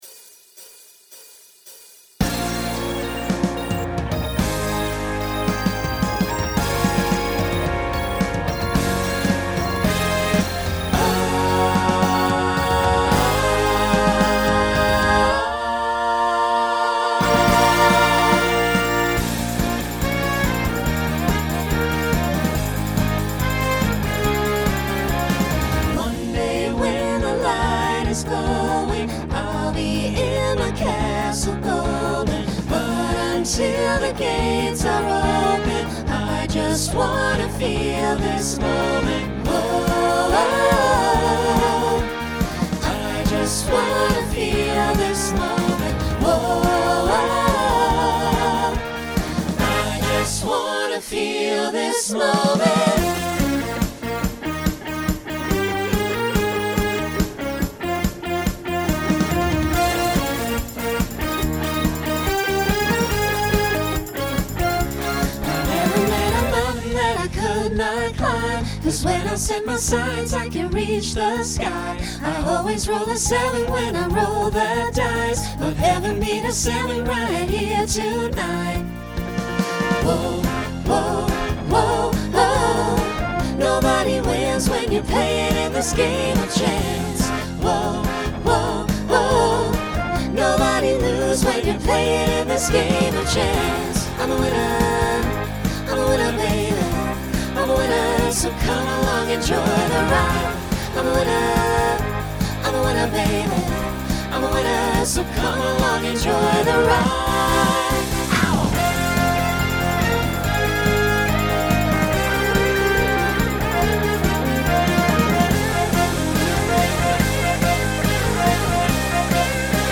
Includes custom bow.
Genre Pop/Dance
Voicing SATB